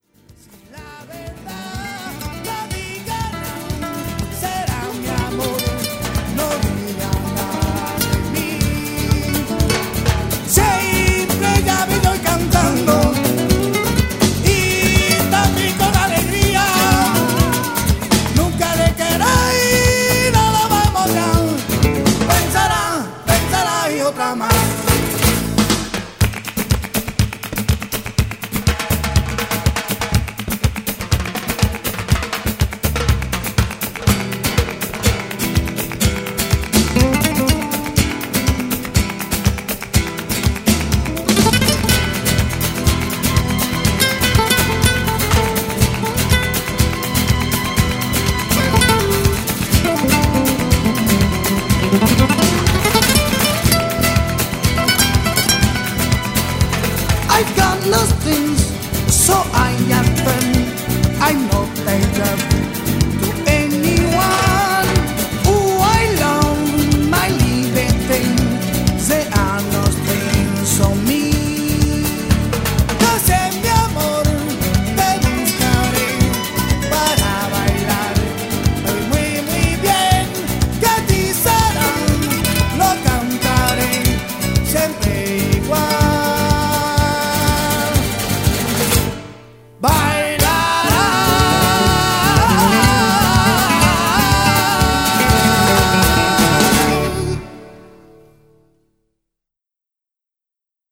この曲の後半からは英語で歌ってます。
もろスパングリッシュですね 笑